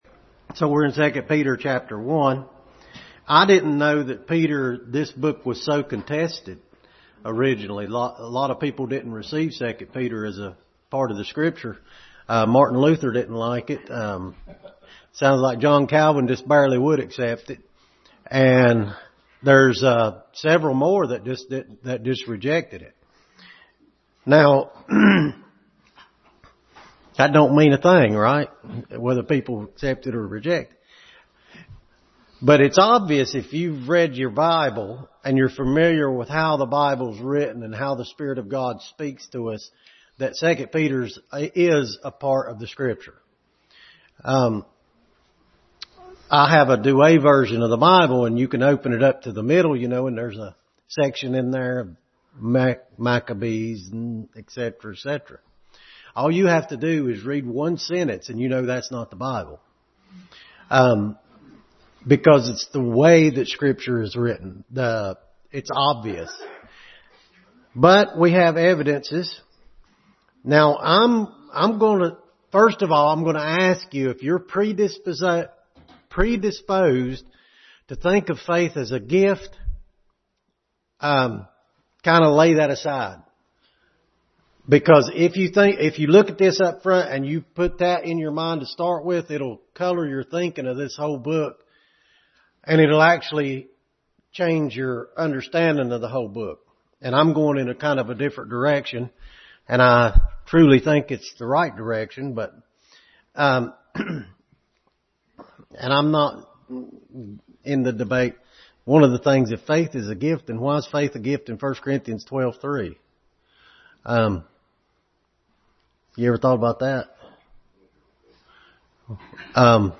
2 Peter 1:1-4 Passage: 2 Peter 1:1-4, Acts 15, Ephesians 2, 3 Service Type: Sunday School